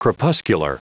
Prononciation du mot crepuscular en anglais (fichier audio)
Prononciation du mot : crepuscular